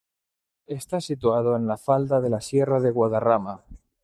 Pronounced as (IPA) /ˈfalda/